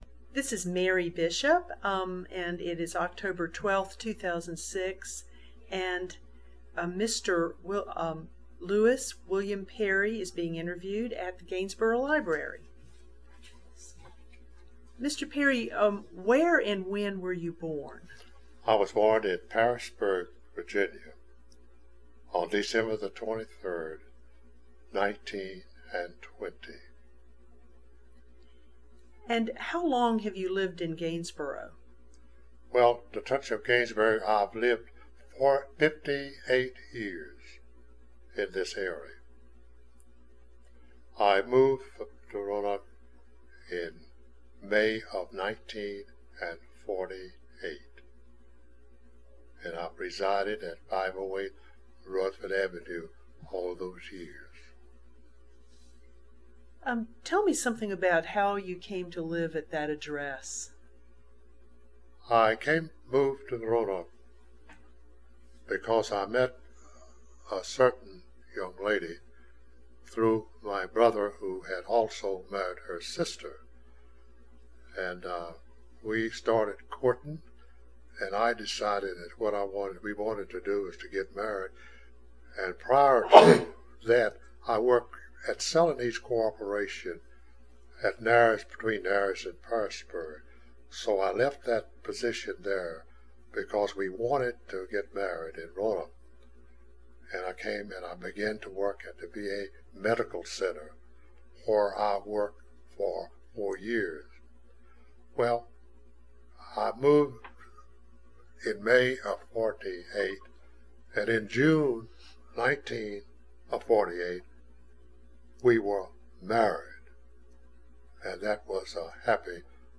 Location: Gainsboro Branch Library